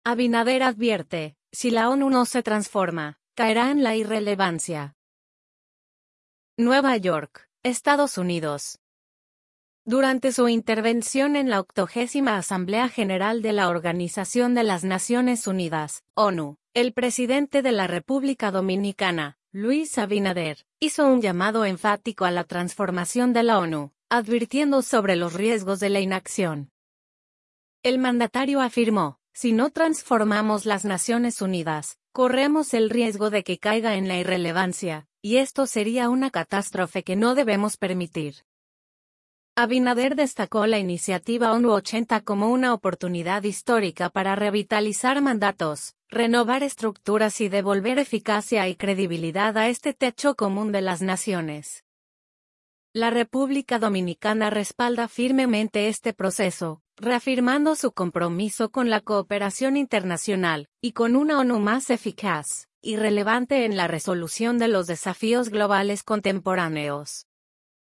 Nueva York, Estados Unidos. – Durante su intervención en la 80ª Asamblea General de la Organización de las Naciones Unidas (ONU), el presidente de la República Dominicana, Luis Abinader, hizo un llamado enfático a la transformación de la ONU, advirtiendo sobre los riesgos de la inacción.